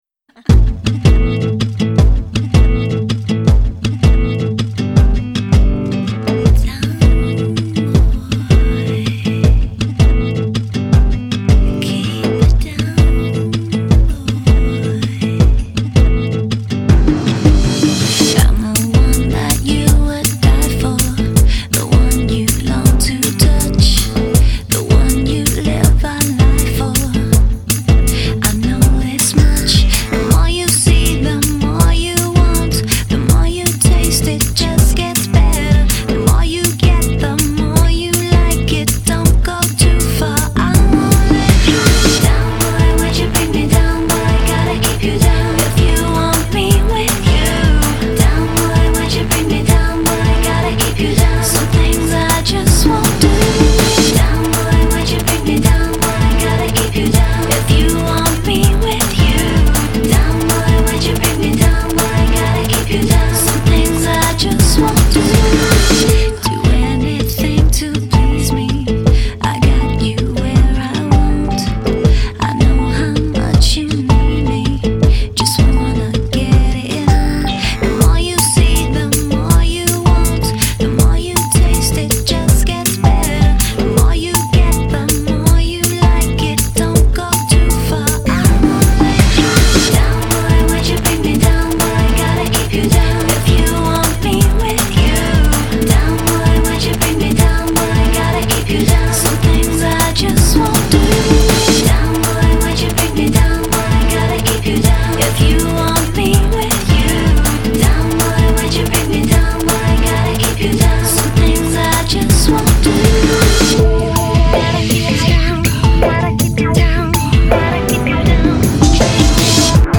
专辑风格：Pop